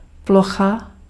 Ääntäminen
IPA: [plɔxa]